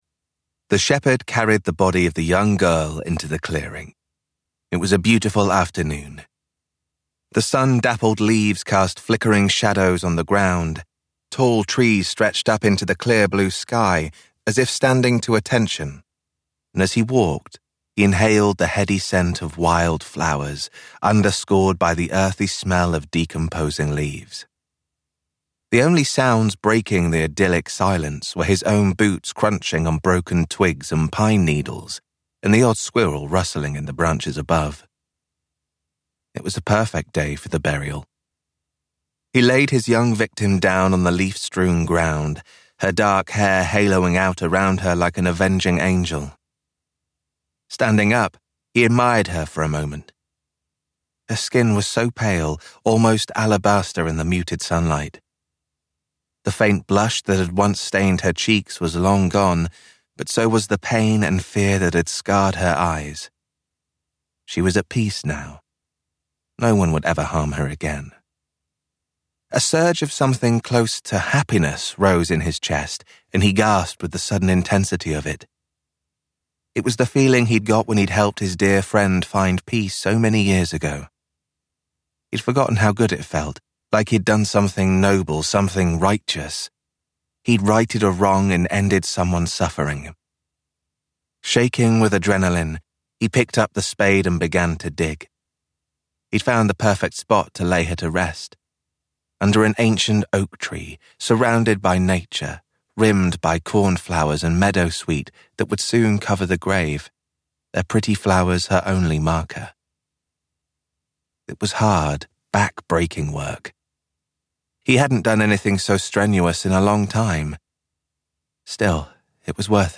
Bisley Wood Murders - Vibrance Press Audiobooks - Vibrance Press Audiobooks